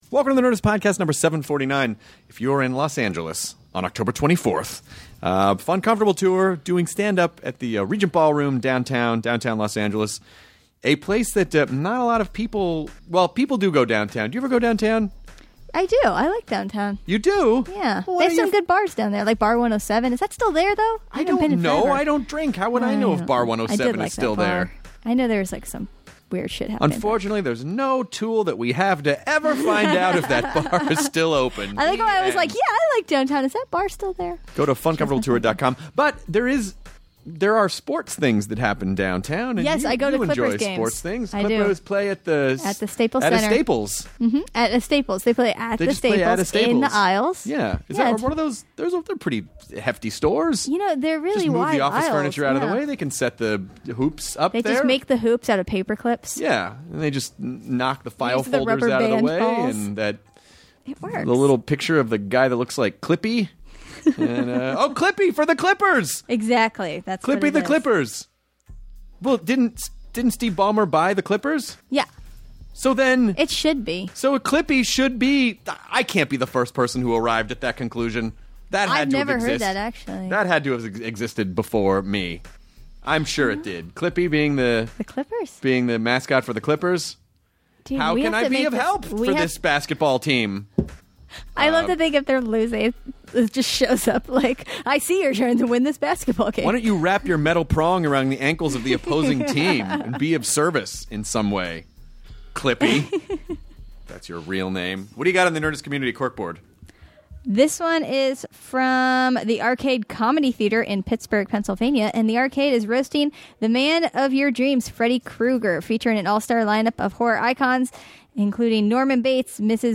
Kirsten Dunst (Spider-Man, Bring It On, Fargo) chats with Chris about living in the valley, what music she likes to listen to and what is was like being a child actor. She also talks about what she likes to do in her free time, upcoming projects she has and what to expect from season 2 of Fargo!